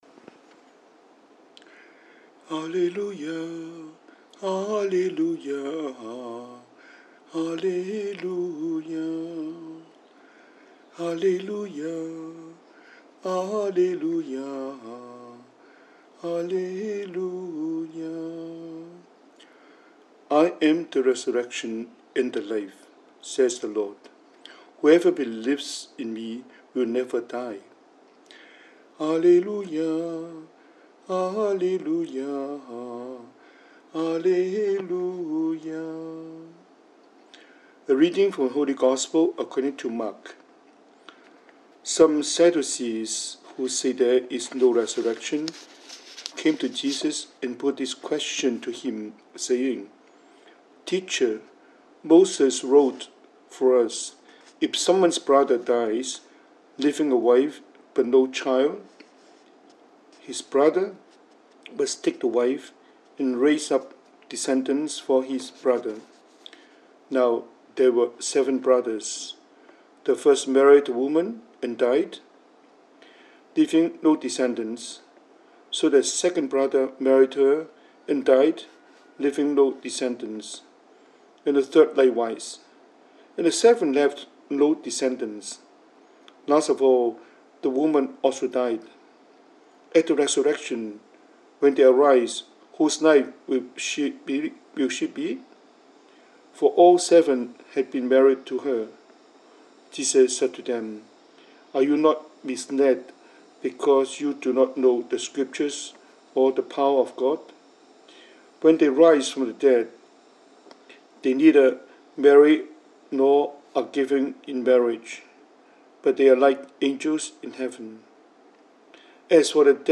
英文講道